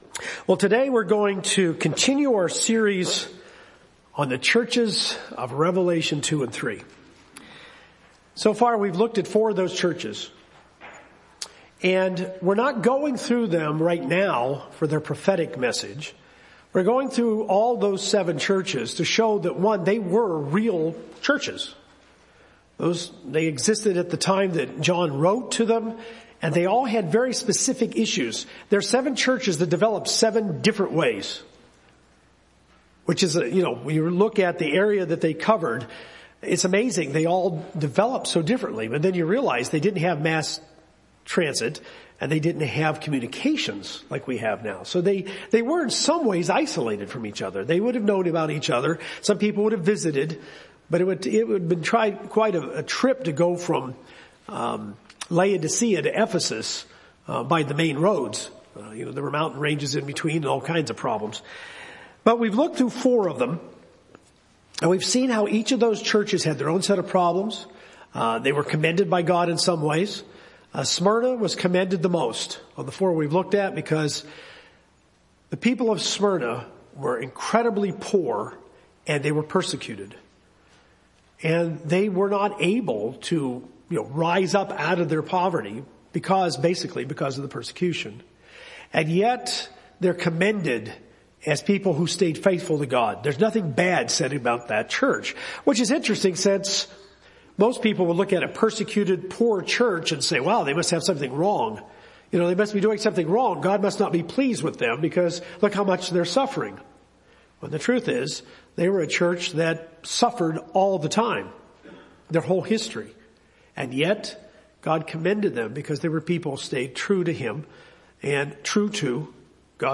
Jesus warns Sardis that although they are alive, they are inwardly dead, and He urges them to strengthen what bits do remain. This sermon covers what causes a church to die and how to guard against it.